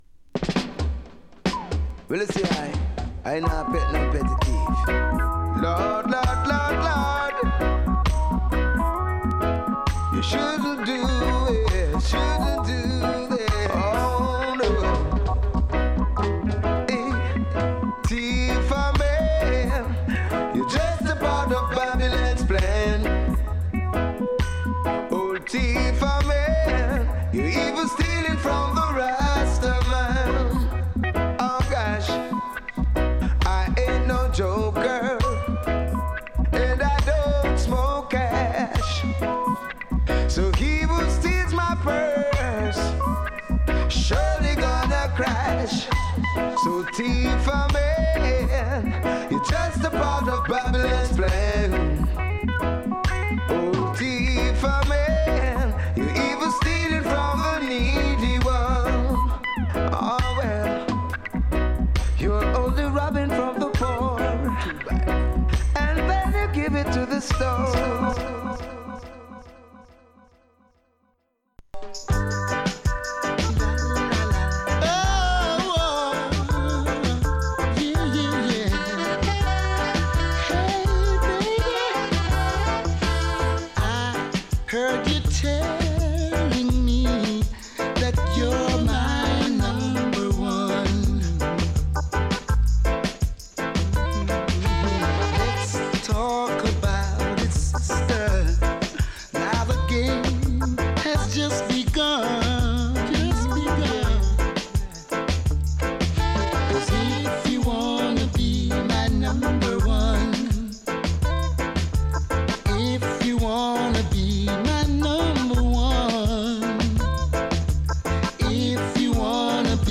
Roots , Rub-A-Dub , Ska / Rocksteady / Early Reggae